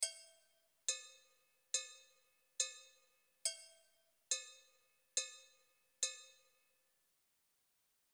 metronom
Metronom zwykle wybija kolejne ćwierćnuty, które liczymy: 1, 2, 3, 4 (raz, dwa, trzy, cztery).
Tak to będzie brzmiało (2 takty):
metro.mp3